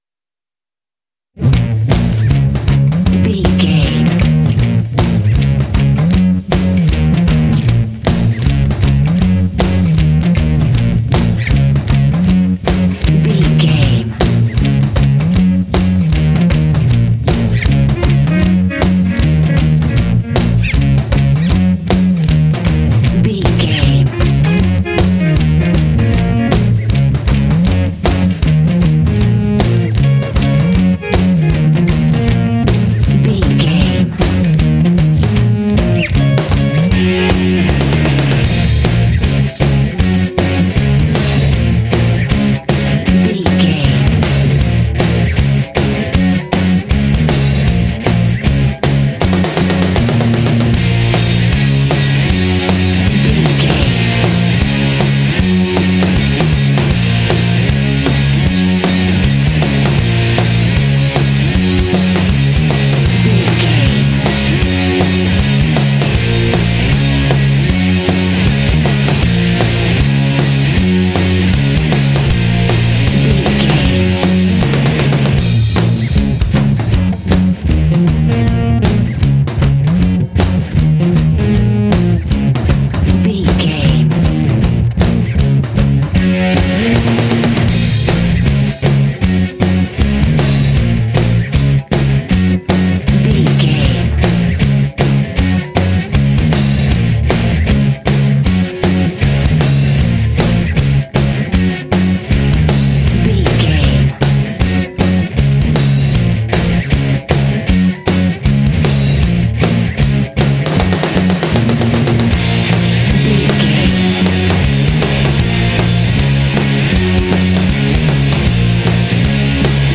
Aeolian/Minor
B♭
driving
energetic
lively
aggressive
bouncy
drums
electric guitar
bass guitar
rock
alternative rock
indie